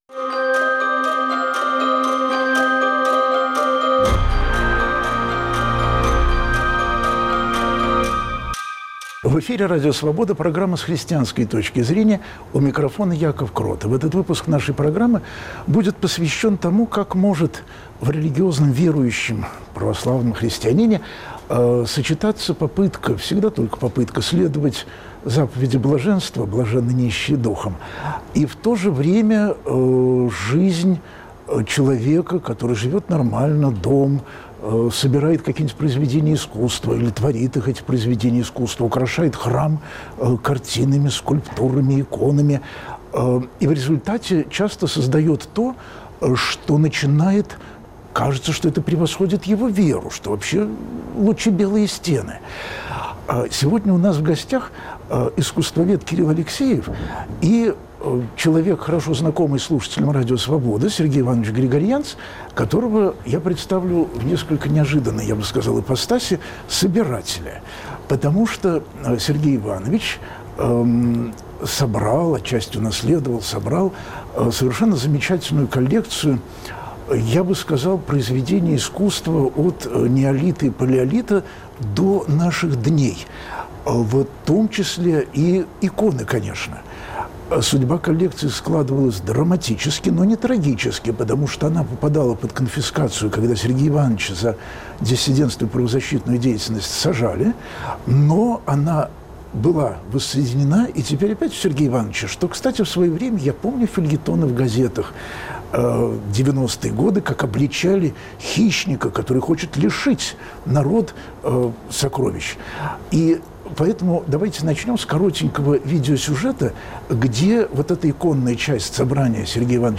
слушают друг друга верующие и неверующие